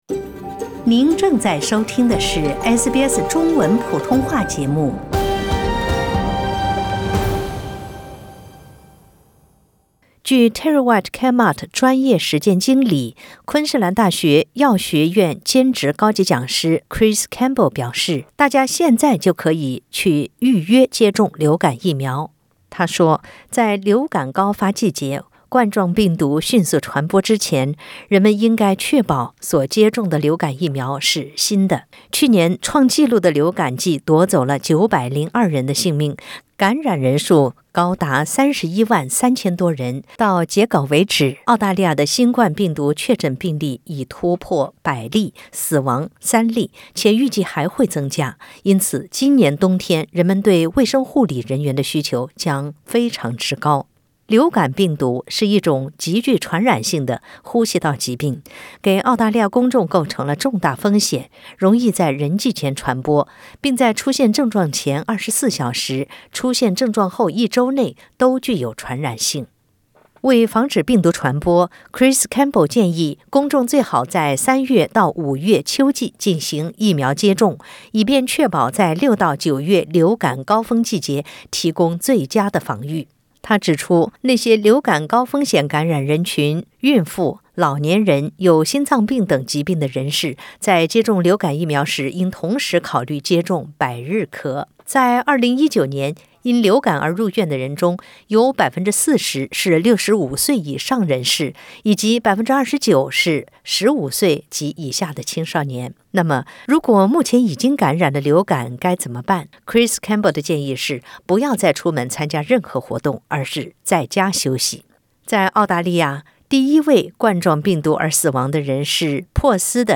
尽管流感疫苗对预防COVID-19无效，但当COVID-19病例增加时，澳大利亚人可以通过接种流感疫苗来减少流感病例数量，从而减轻对公共卫生系统的负担。点击上方图片收听音频报道。